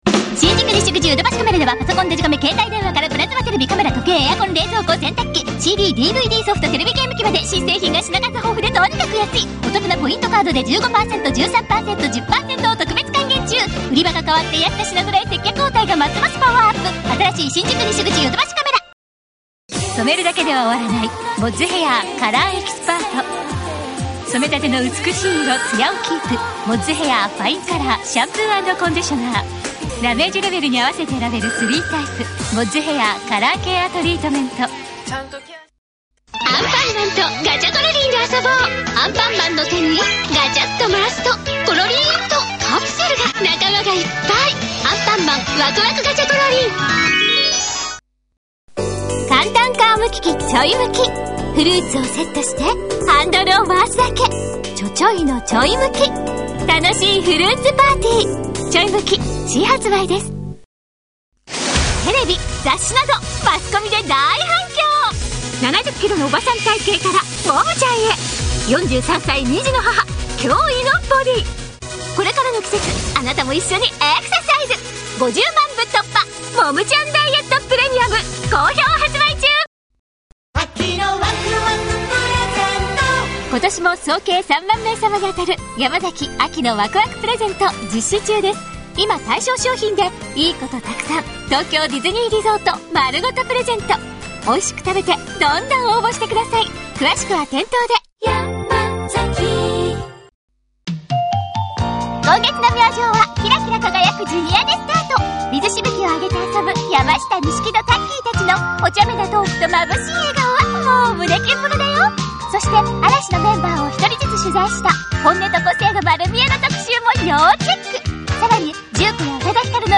CM色々　CM色々 1 超速CM
2 モード系
5 元気
7 萌え系
8 しっとり